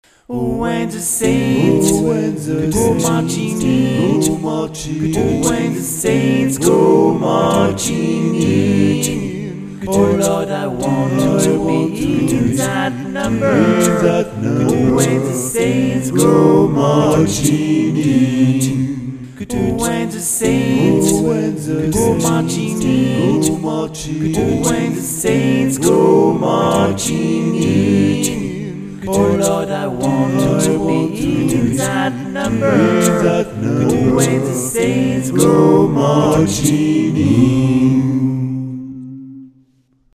Atelier Chant Polyphonique A Capella Adultes (Nouveau !!!)
Il s'agit d'une approche moderne du chant chorale, incluant des voix mélodiques, rythmiques, des percussions vocales (beat box) et corporelles.